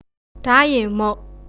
Retroflex
Da-re-mhoat {Ða.ré-mhoat}